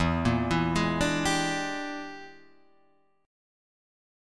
E7#9b5 chord